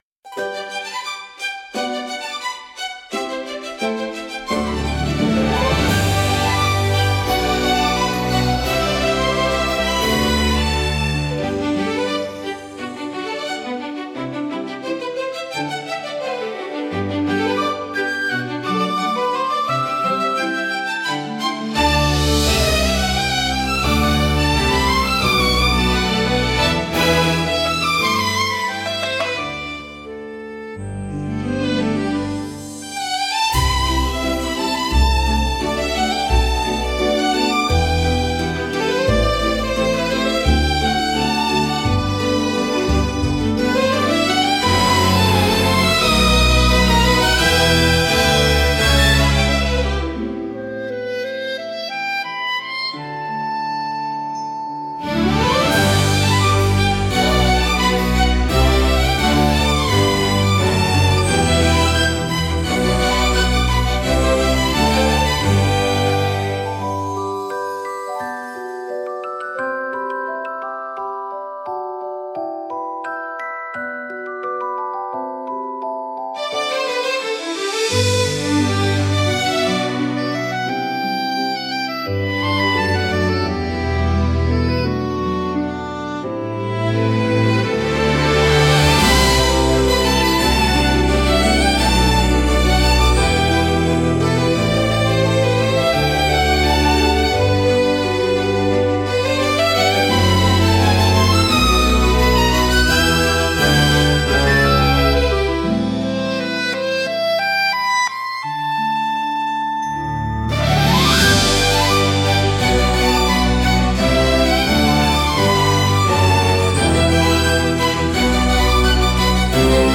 オリジナルのウェディングは、幸福感や愛情をドラマチックに表現した曲調が特徴のジャンルです。
美しいメロディと壮大で感動的なアレンジが、祝福と喜びの空気を豊かに演出します。
ロマンティックで華やかな場にぴったりのジャンルです。